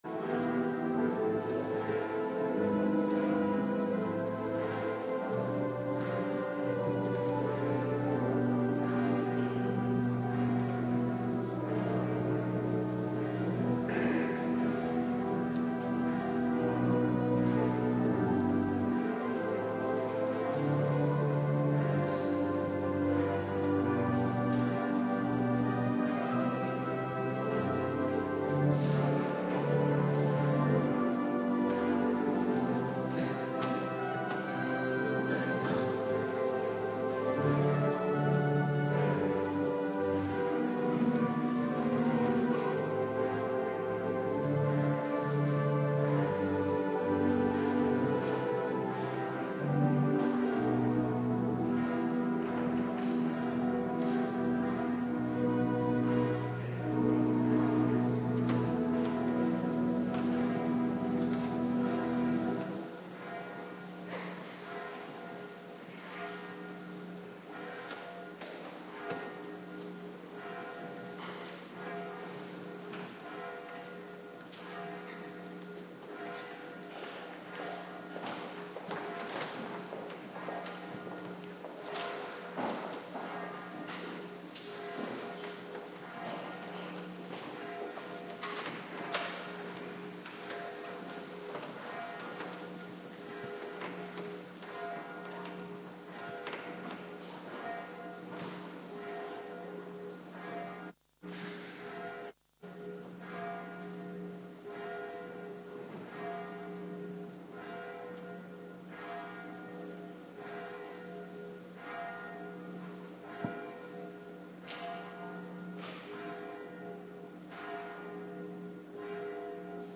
Avonddienst Hervormingsdienst
Locatie: Hervormde Gemeente Waarder